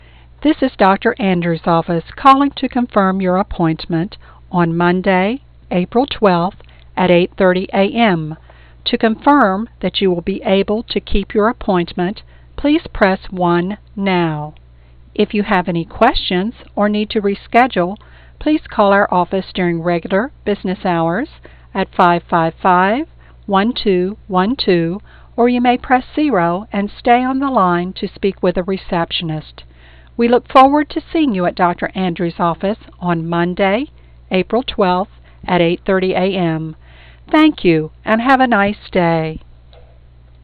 And you'll deliver your messages with professionally recorded, crystal-clear 16 bit sound.